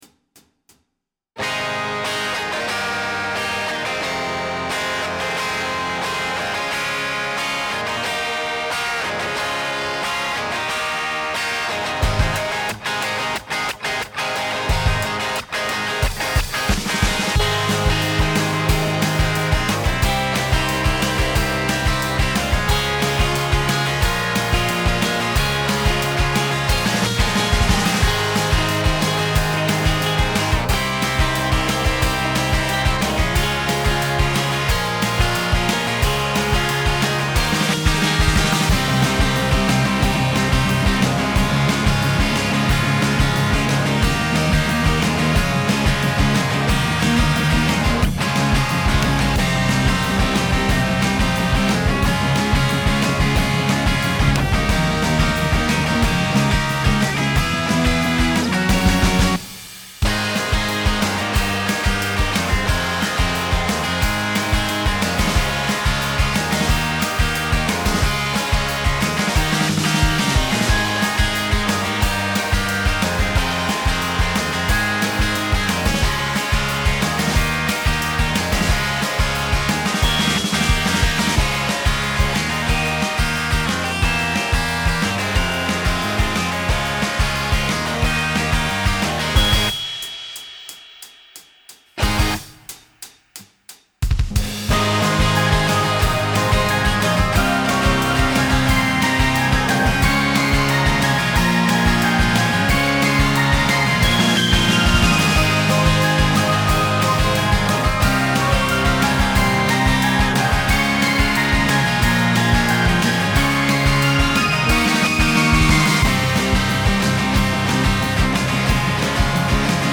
今回は前回と違ってアップテンポで元気な曲ですが、どちらも違った魅力があって良い曲です！
曲の方向性としては、「アニメに出てくるような女子高生の初心者ガールズバンドの最初のオリジナル曲」みたいな印象で、バンドサウンドを基調に作りました。
めっちゃ演奏が上手とかじゃなくて、「好きなものを前のめりな勢いで全力で表現する」みたいなイメージです。
カラオケ音源